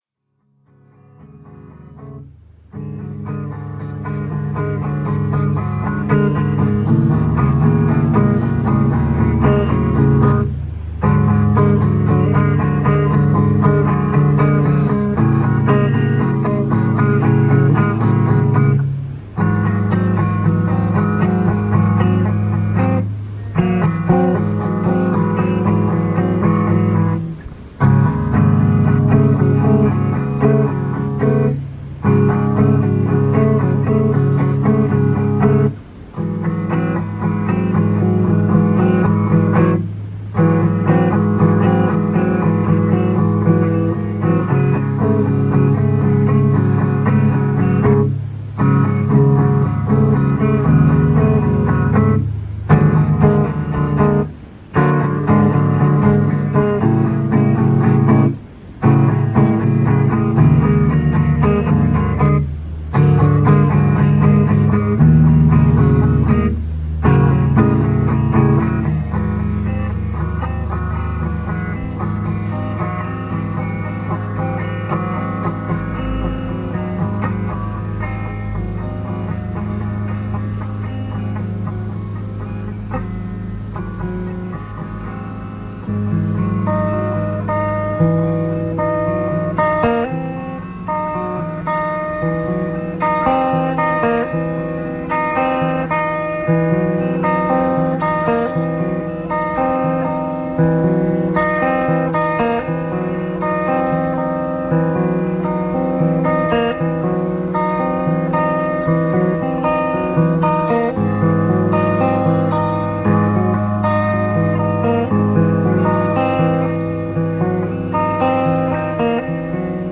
A little collage of song's fragment from band's practices: